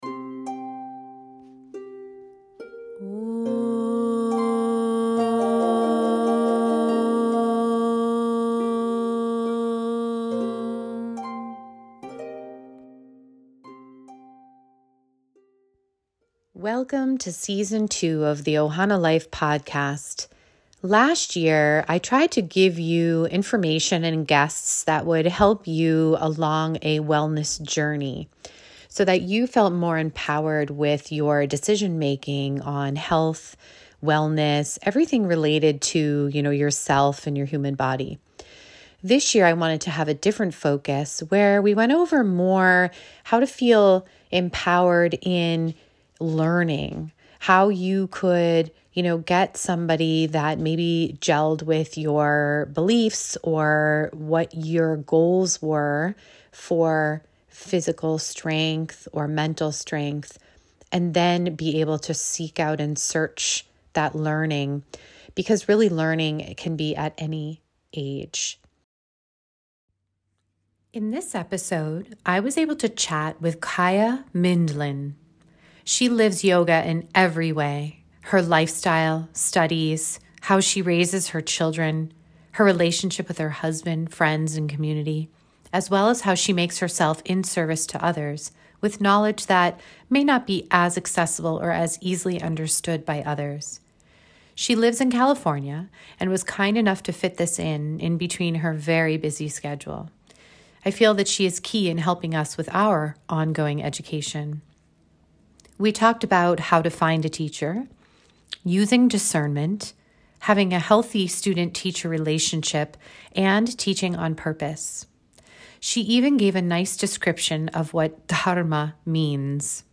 We talked about how to find a teacher, using discernment, having a healthy student/teacher relationship and teaching on purpose. She even gave a nice description of what Dharma means. It was a very simple conversation but covered some important topics in depth.